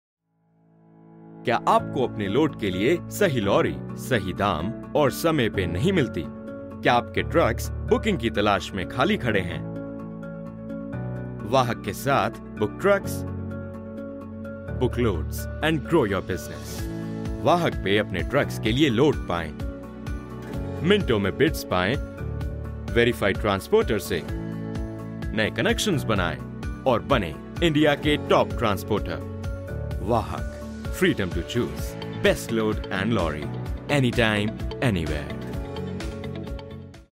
专题解说【清新自然】